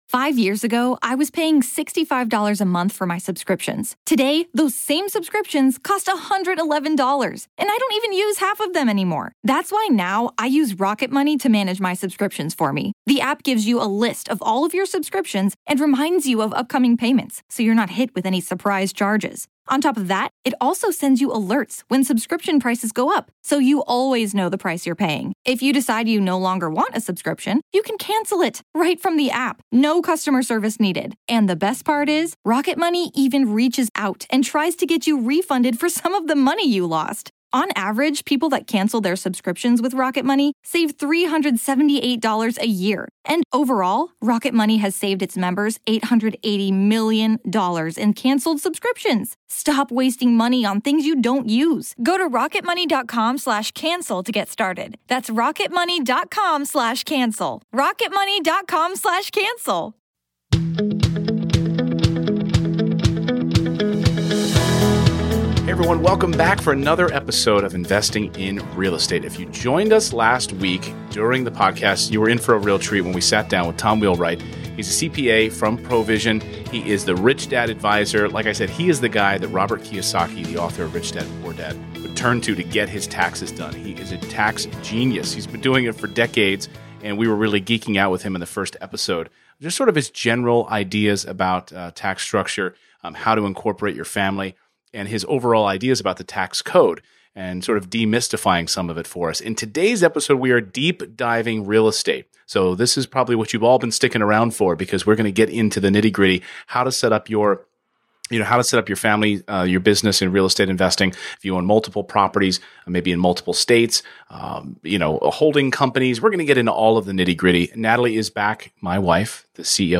EP203: How to Maximize Depreciation - Interview